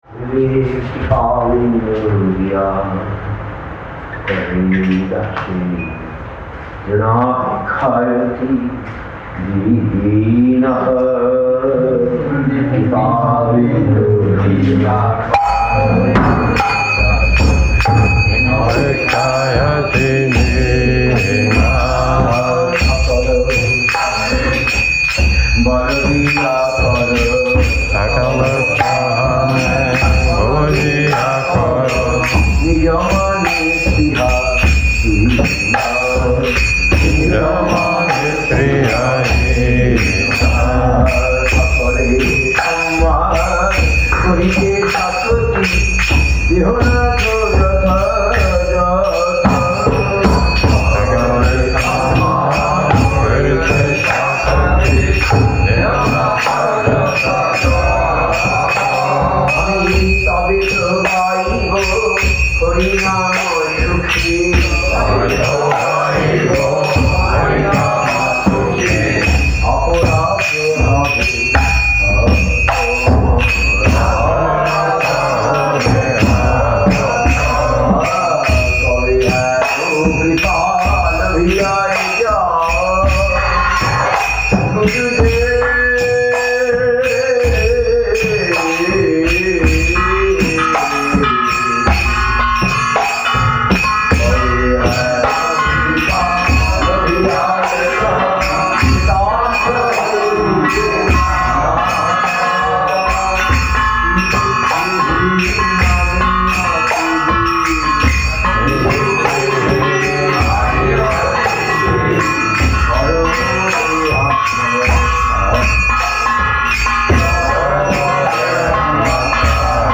Place: SCSMath Nabadwip
Tags: Kirttan